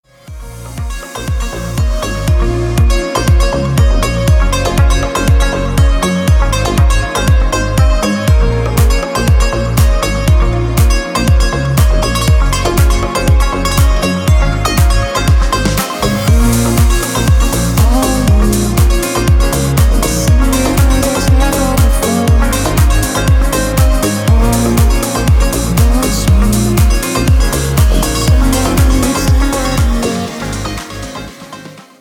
• Качество: 320, Stereo
мужской голос
deep house
мелодичные
Electronic
EDM
спокойные
Спокойный deep house рингтон